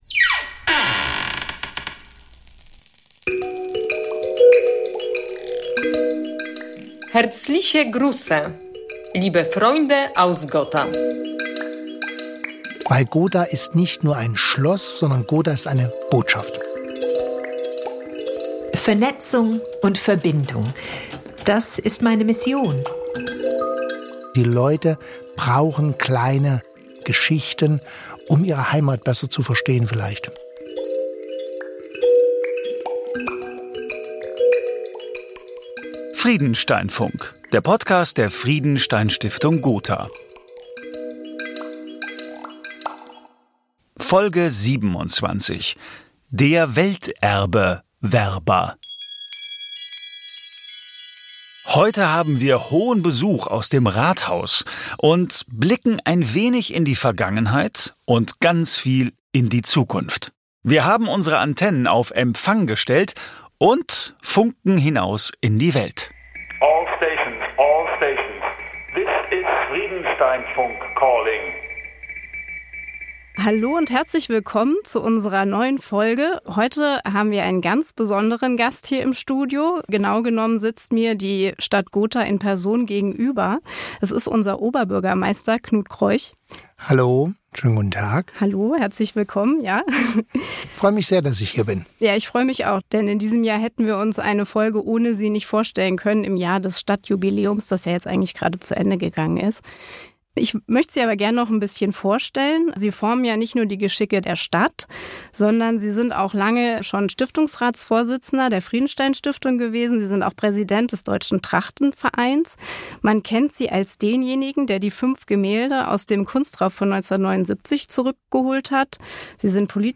Gotha hat ein Jahr lang Geburtstag gefeiert! Was plant die Stadt für die nächsten 1.250 Jahre? Wir fragen unseren Oberbürgermeister.